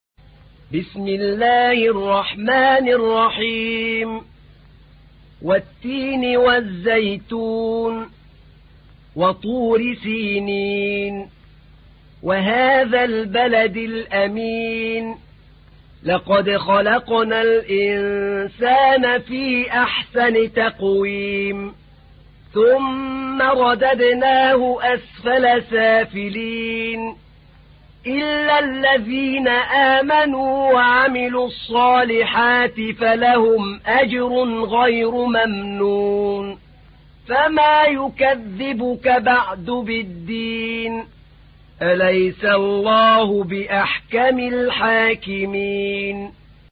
تحميل : 95. سورة التين / القارئ أحمد نعينع / القرآن الكريم / موقع يا حسين